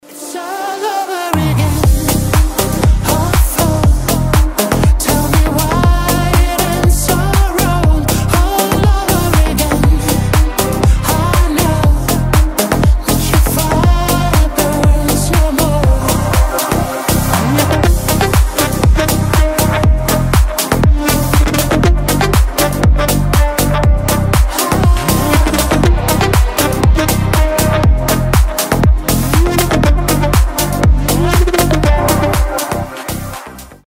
• Качество: 320, Stereo
мужской голос
deep house
progressive house
Хороший отрывок эмоциональной песни